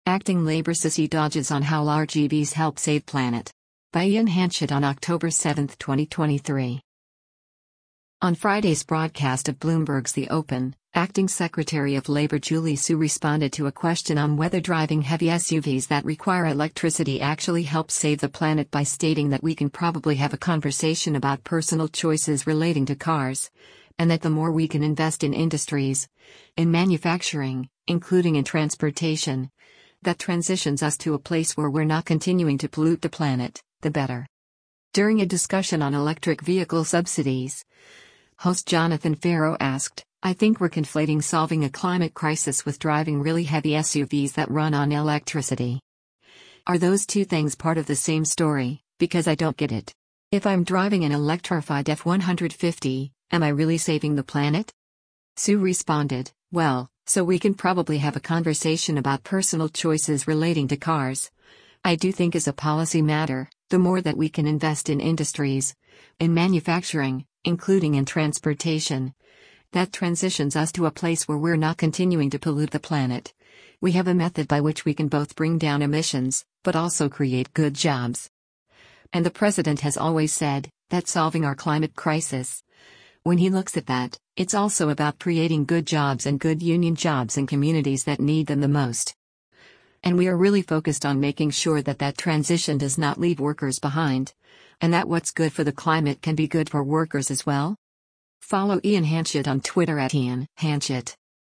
On Friday’s broadcast of Bloomberg’s “The Open,” acting Secretary of Labor Julie Su responded to a question on whether driving heavy SUVs that require electricity actually helps save the planet by stating that “we can probably have a conversation about personal choices relating to cars,” and that the more “we can invest in industries, in manufacturing, including in transportation, that transitions us to a place where we’re not continuing to pollute the planet,” the better.